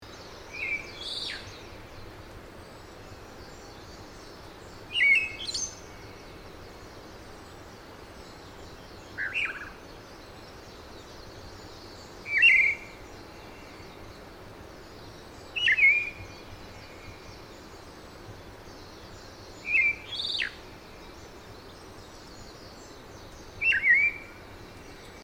6ootoratugumi.mp3